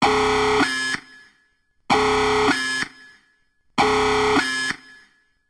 siren.wav